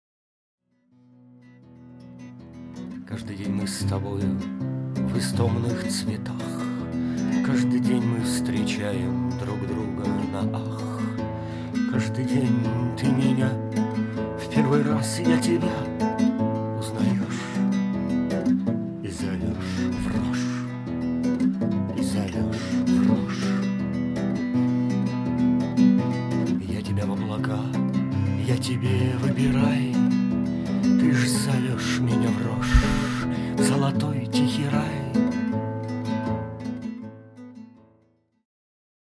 ~Влюбленный, Эротичный голос~.mp3
Мужской
Баритон